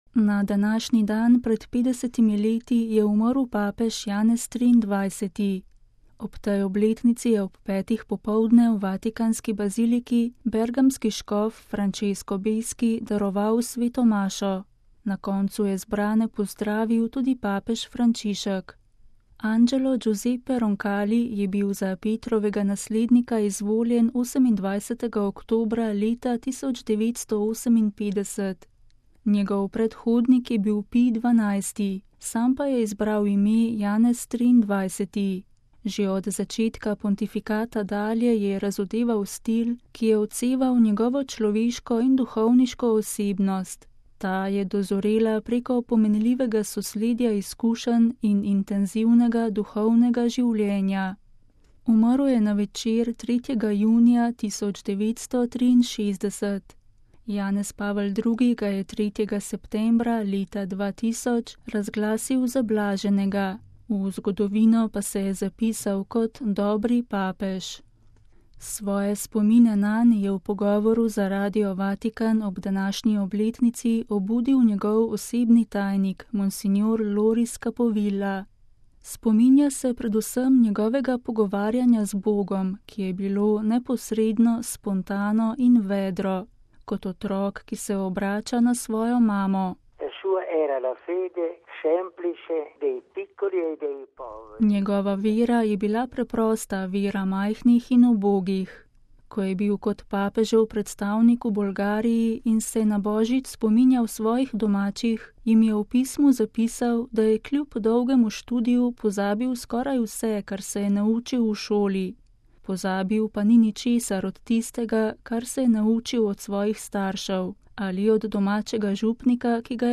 Svoje spomine nanj je v pogovoru za Radio Vatikan ob današnji obletnici obudil njegov osebni tajnik, msgr.